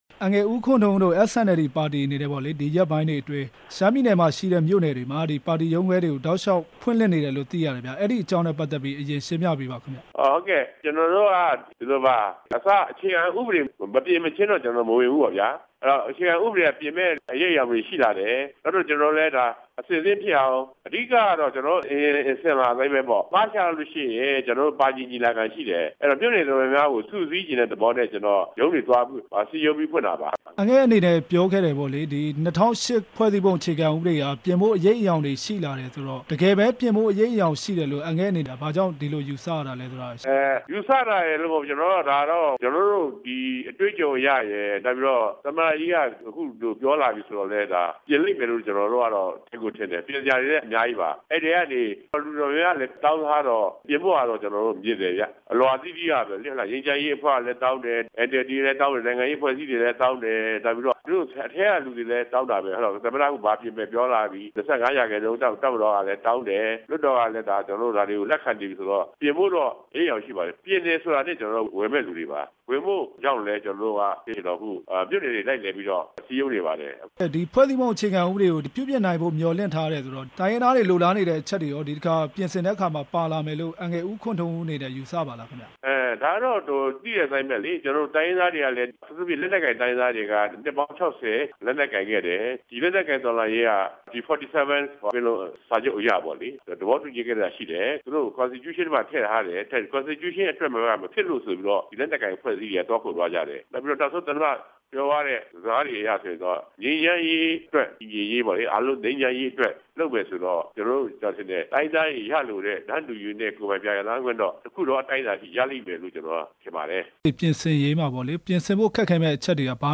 SNLD ပါတီ ရွေးကောက်ပွဲဝင်ရေး ဆက်သွယ်မေးမြန်းချက်